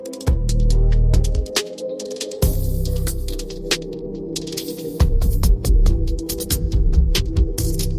generate a drill rap song